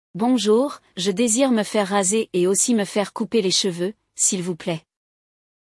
Hoje, vamos ouvir dois rapazes conversando em uma barbearia.